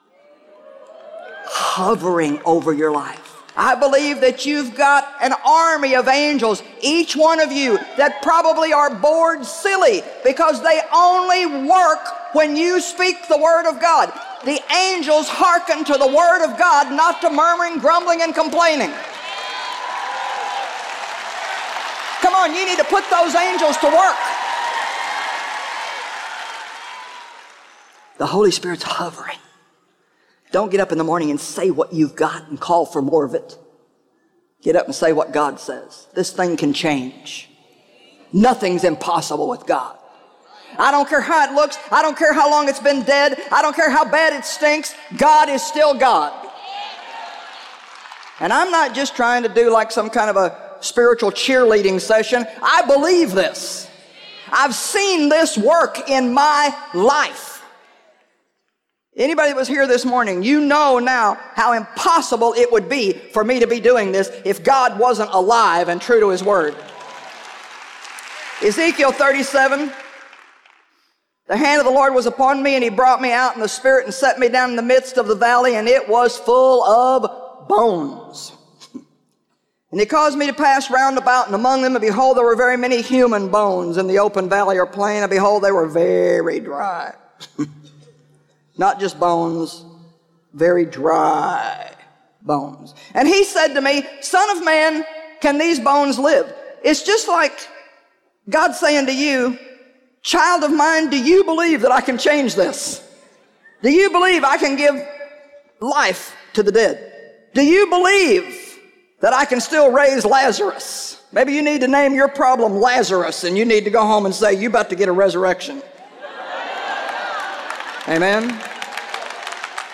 Unleashing the Power of Faith Audiobook
Narrator
Joyce Meyer
2.58 Hrs. – Unabridged